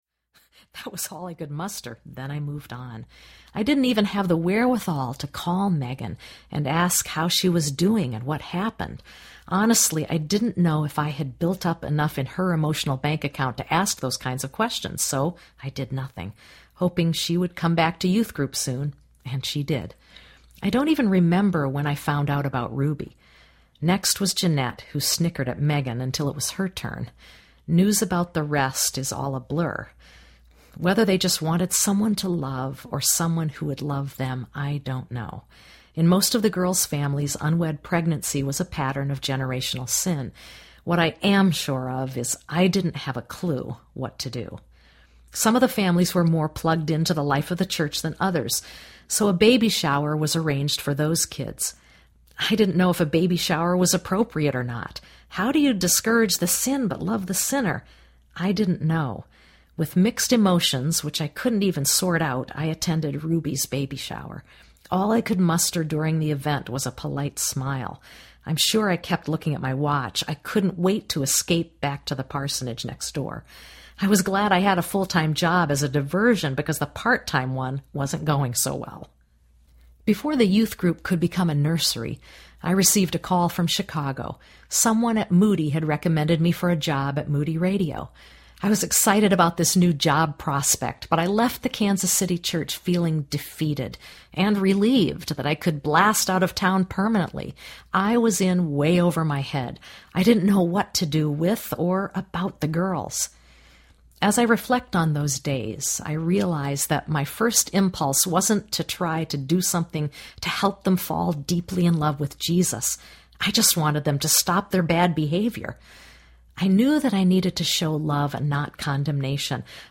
What Women Tell Me Audiobook